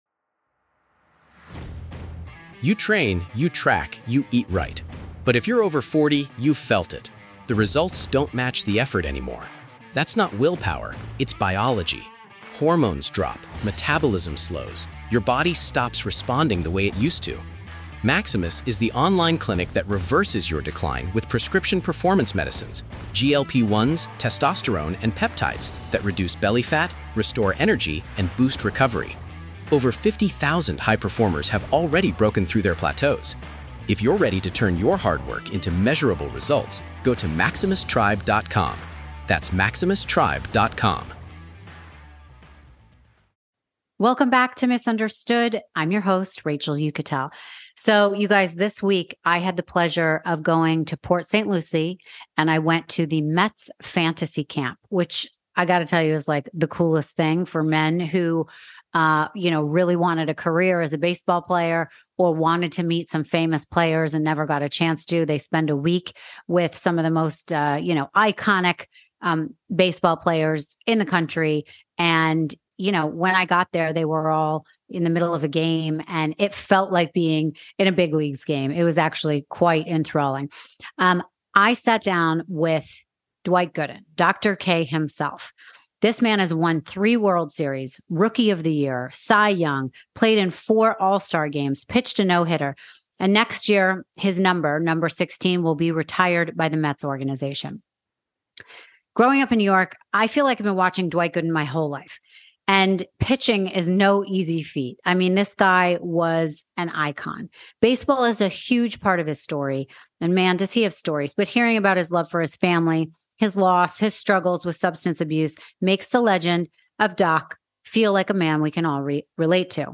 Today's guest is the legendary pitcher Dwight Gooden! We not only got to chat with Dr. K, but we got to do it in the dugout at the Mets training facility during Fantasy Camp.